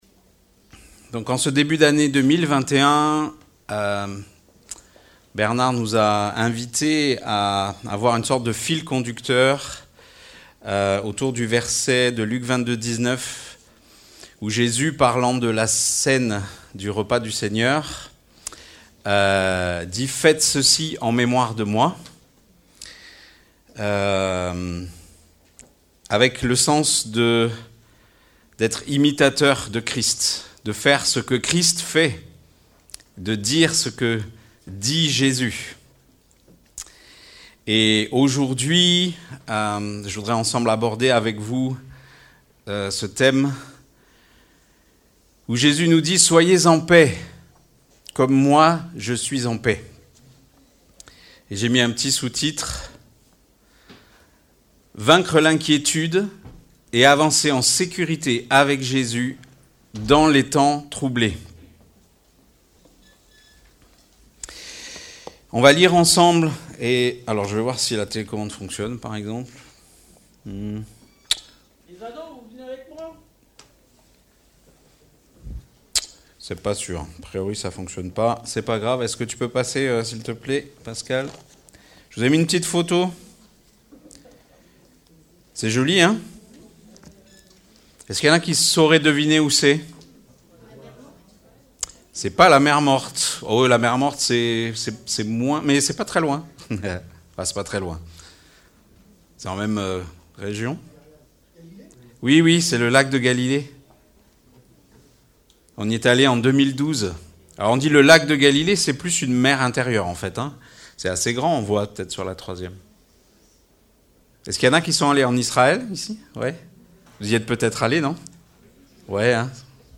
Marc 4:35-41 Type De Service: Culte Vaincre l'inquiétude et avancer en sécurité avec Jésus dans les temps troublés.